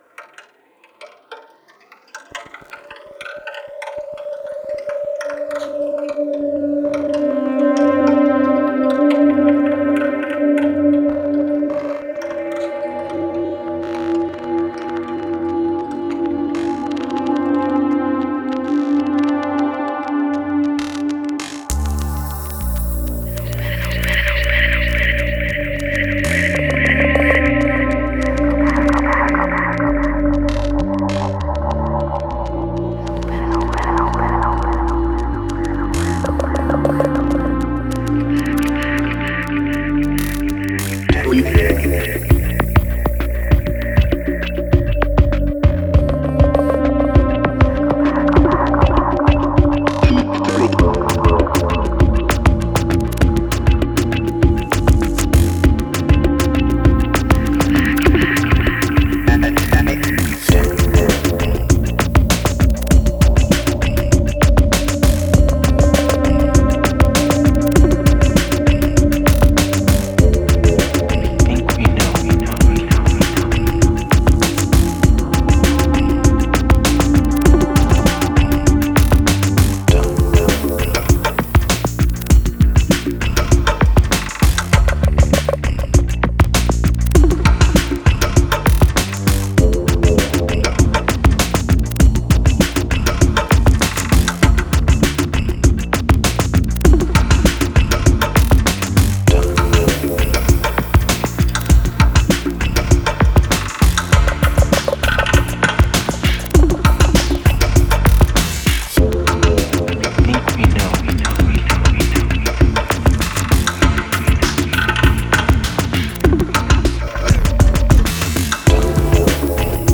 Genre: IDM, Glitch, Downtempo.